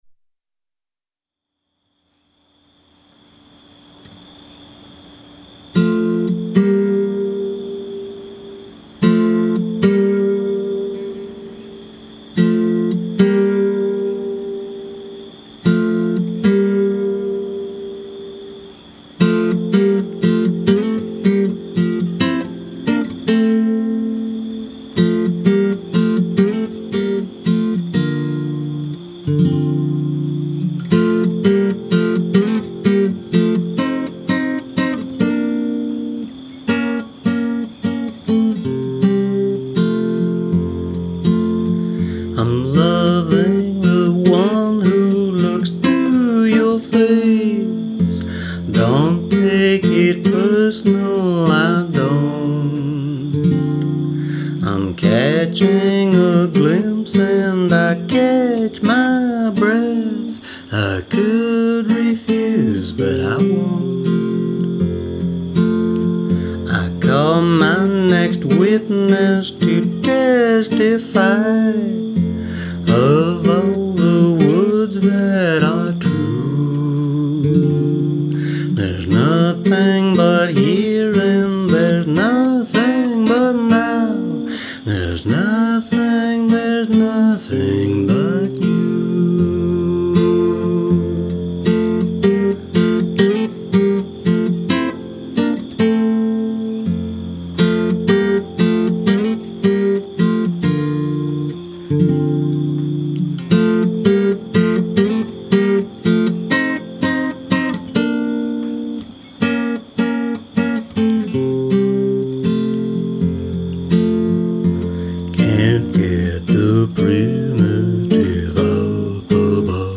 Bit of beside the campfire down by the swamp music. Voice and guitar. The crickets are real.